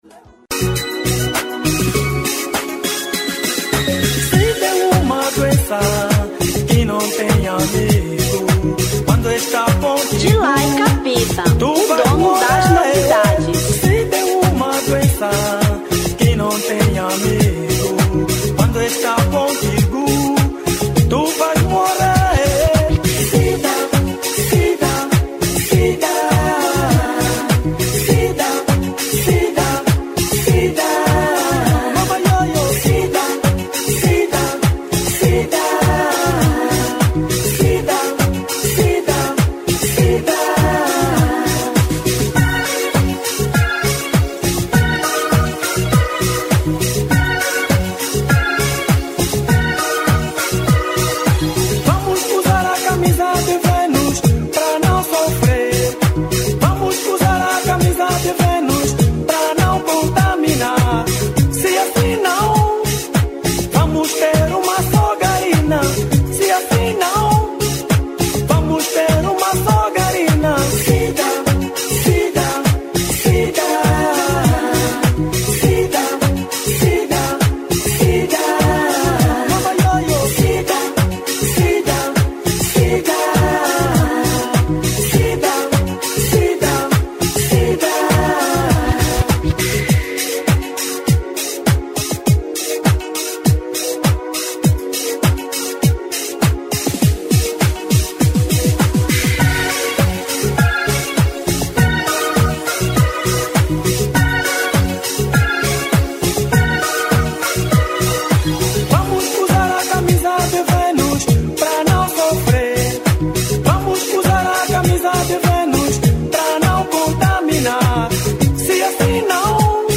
Kizomba 2000